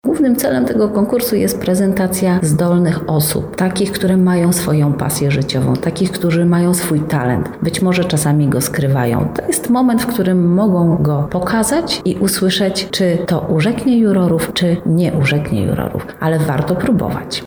Konkurs-wywiad-1_01.mp3